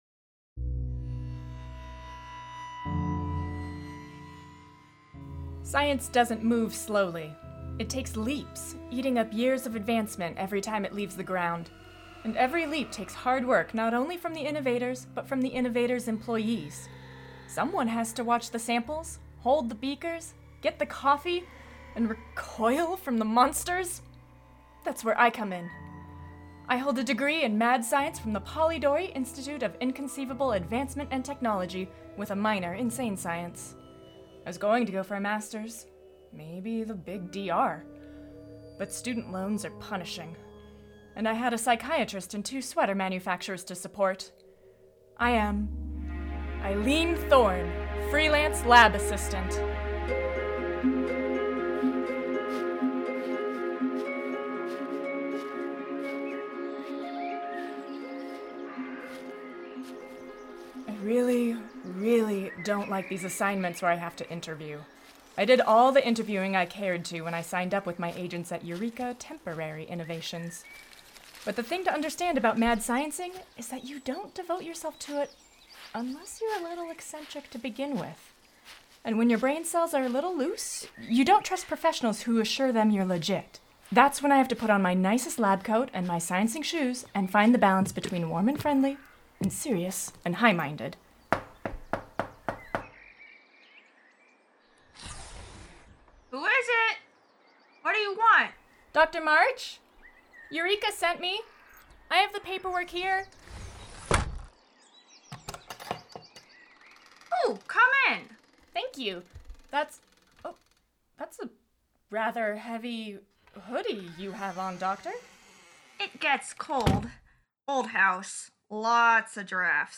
best audio drama
comedy